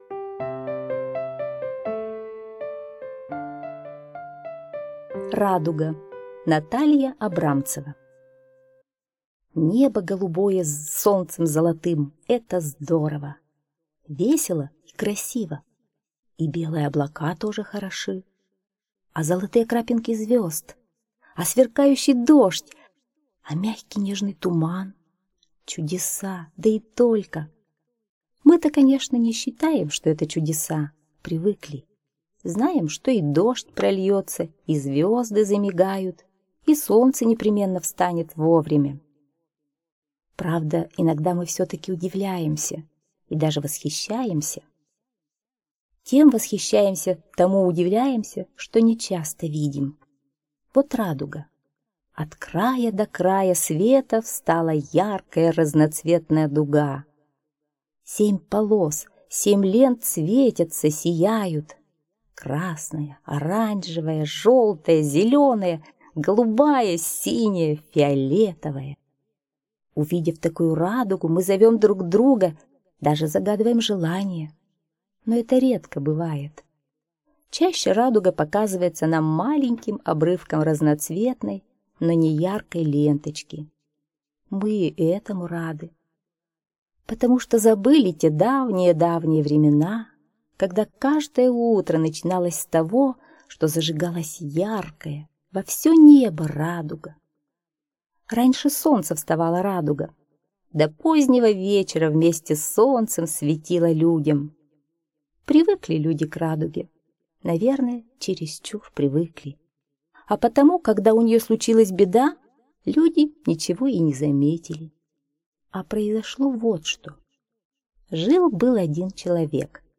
Слушайте Радуга - аудиосказка Абрамцевой Н. Давным-давно радуга была на небе целый день, она вставала раньше солнца и до позднего вечера светила людям.